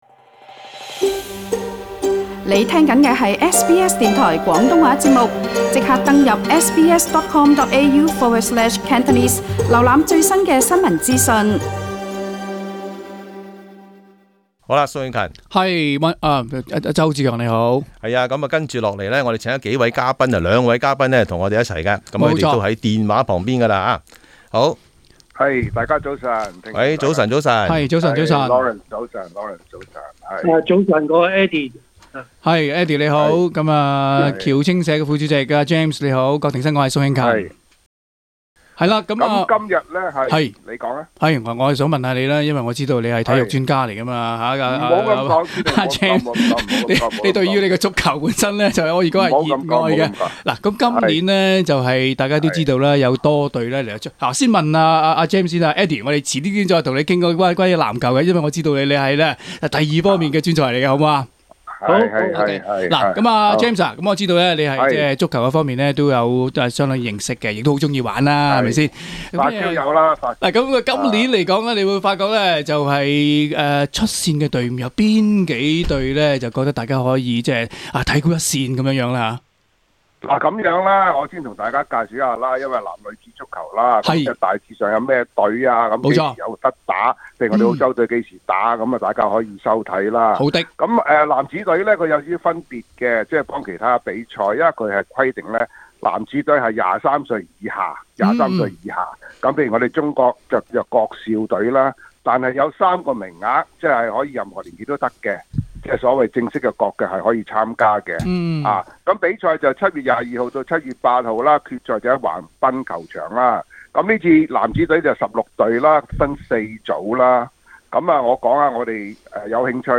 體育專訪：奧運前瞻